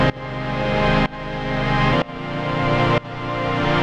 GnS_Pad-MiscA1:2_125-C.wav